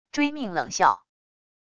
追命冷笑wav音频